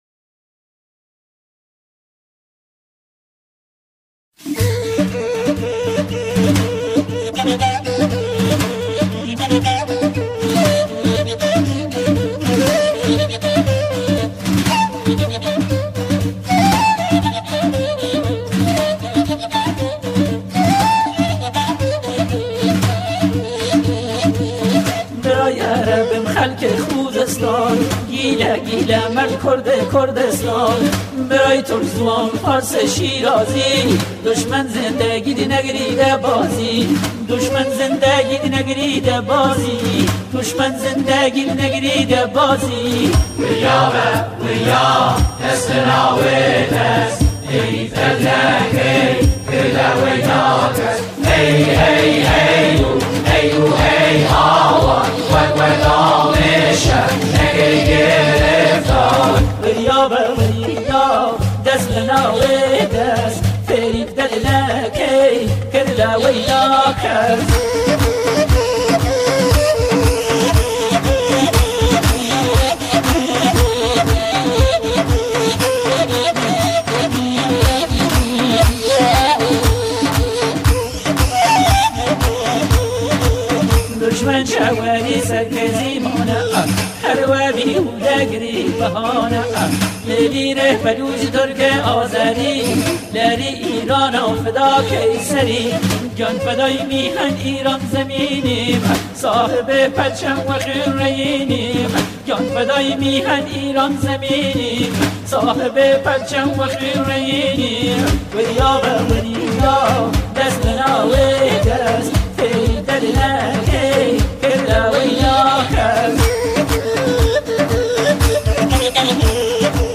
همخوانی